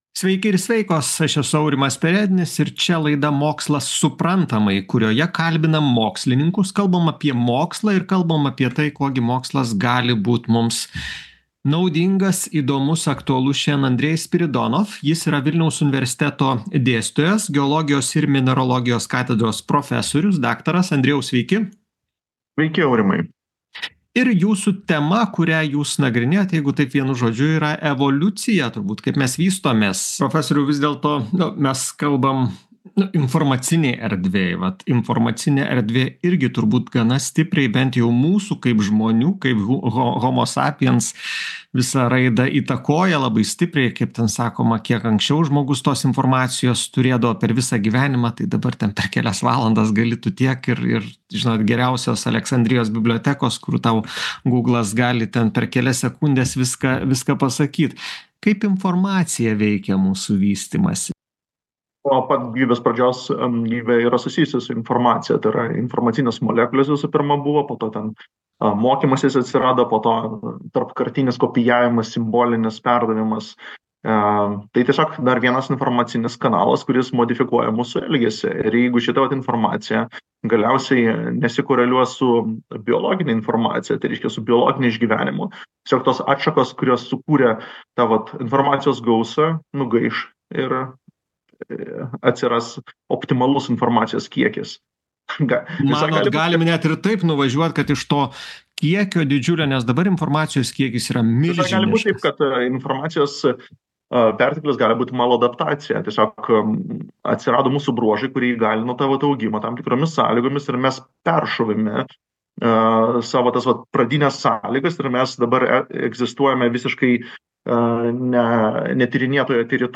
Įvairius žmonijos evoliucijos aspektus aptariame su evoliucijos tyrinėtoju